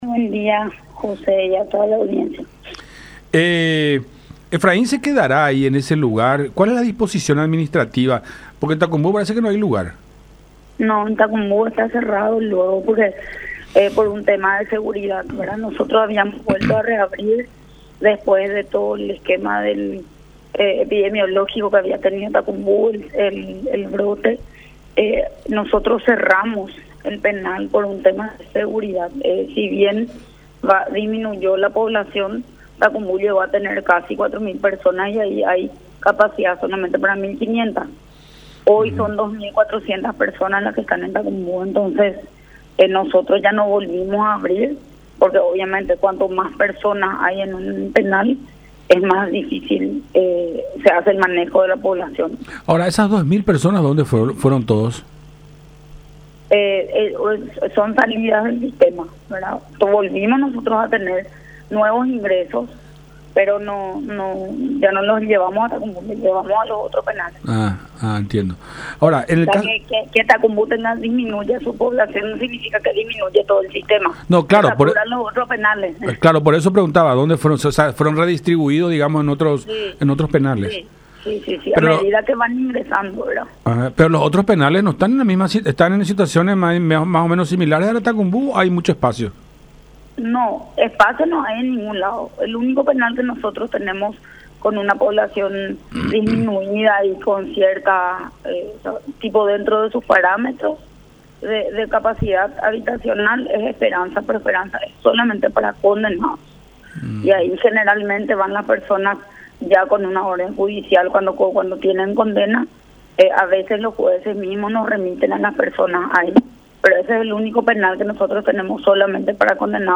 “Cerramos el penal de Tacumbú por una cuestión de seguridad y también por el tema del rebrote de COVID-19”, expuso Pérez en diálogo con La Unión.